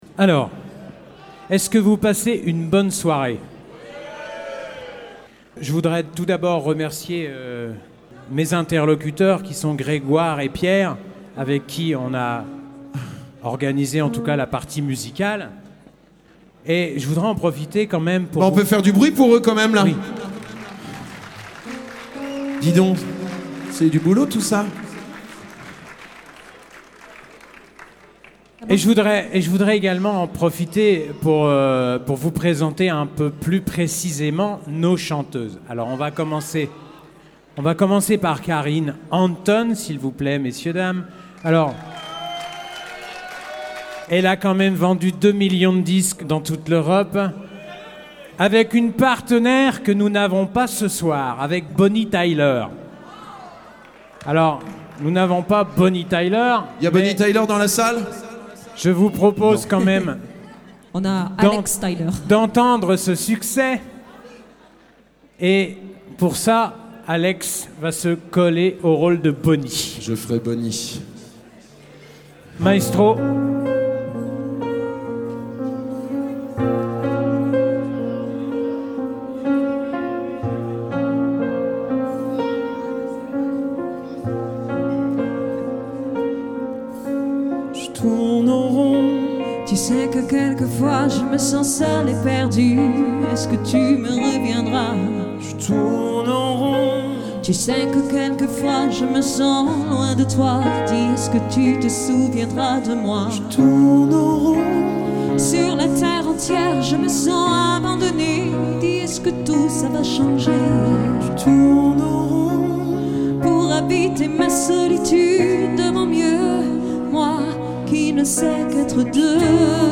Programme d'orchestre de variété avec chanteuse et chanteur
Soirée de Gala des 100 ans d'Arcelor-Mittal - St-Chély d'Apcher